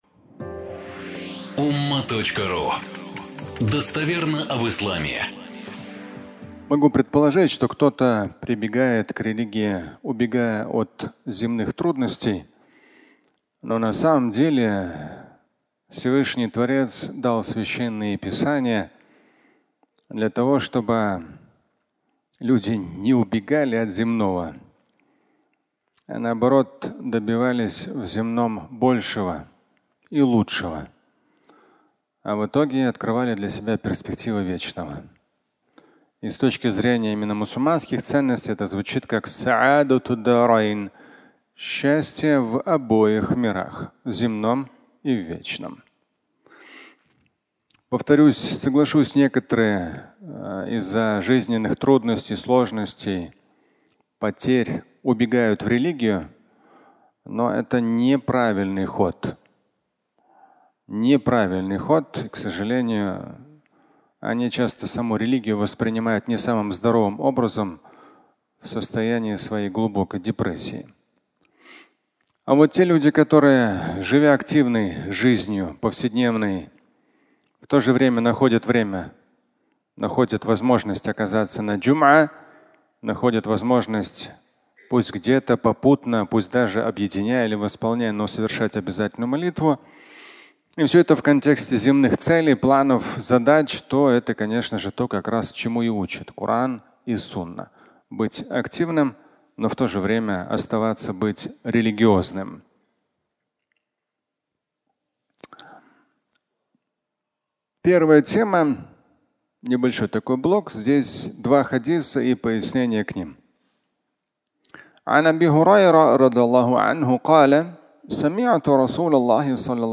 Господи, прости! (аудиолекция)